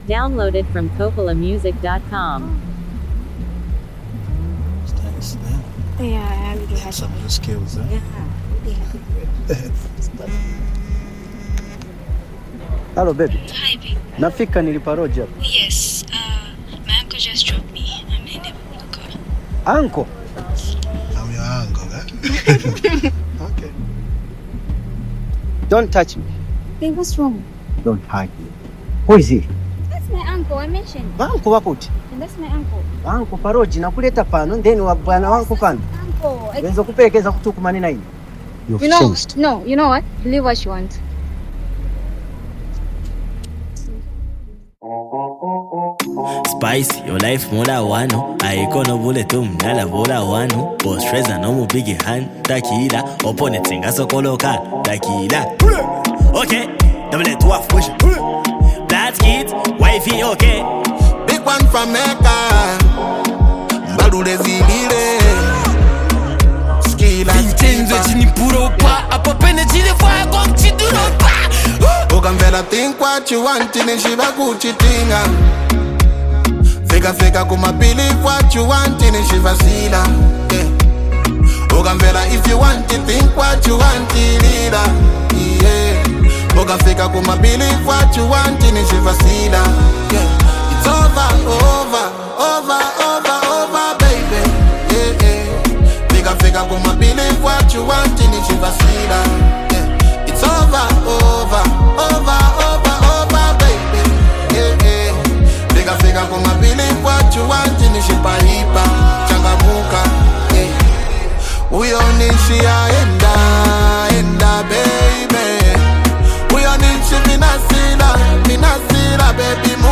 a bold, self-assured anthem
a reflective and melodic approach